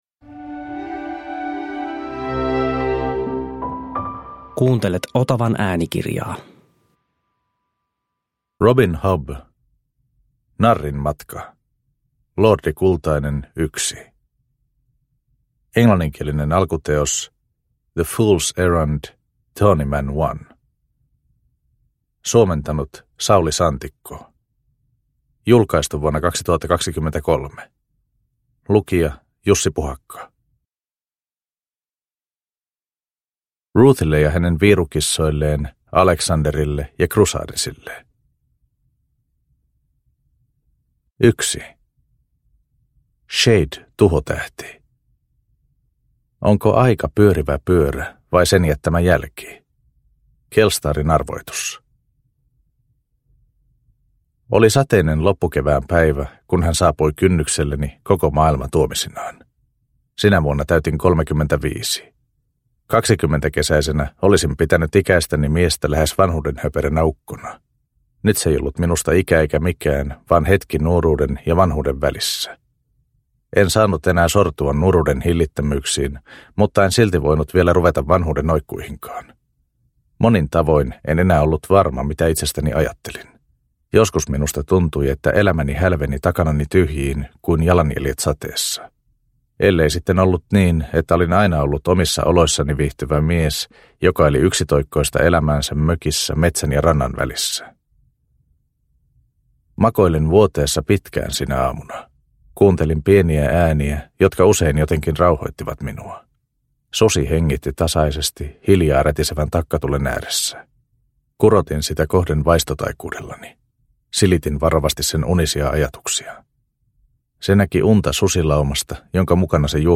Narrin matka – Ljudbok – Laddas ner